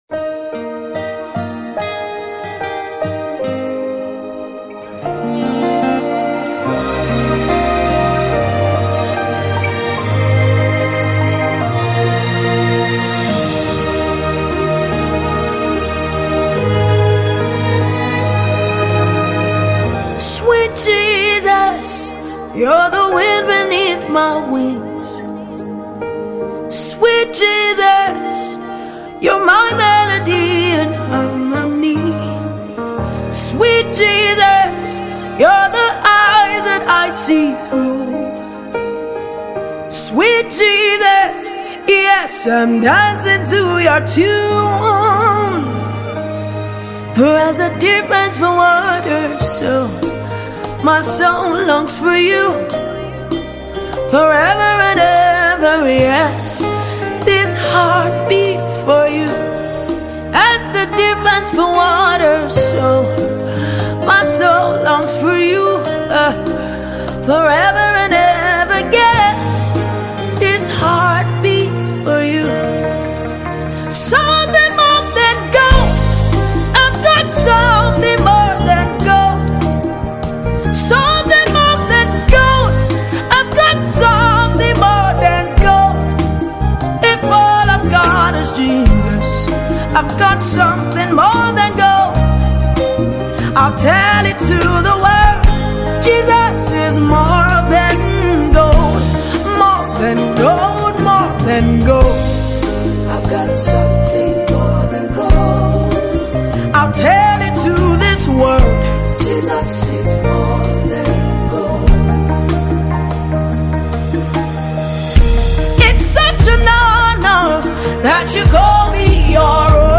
And it’s just anointed and refreshing.